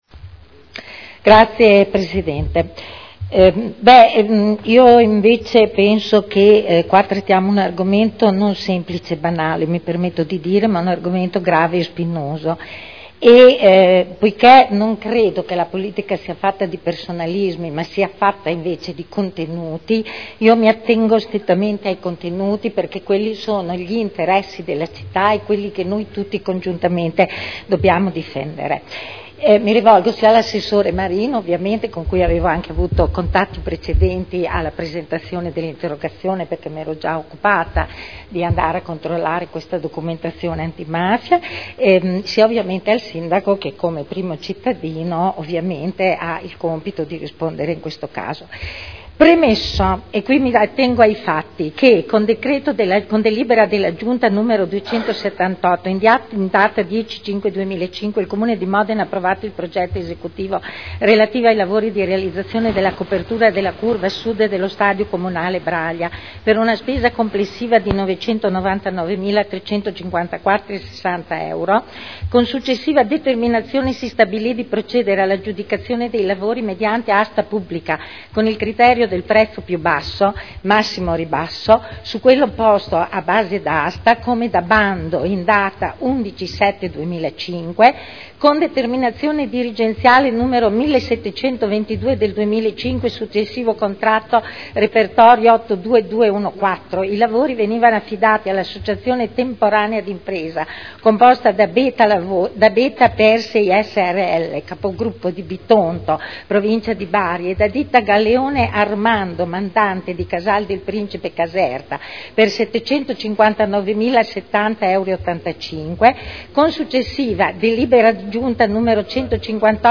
Eugenia Rossi — Sito Audio Consiglio Comunale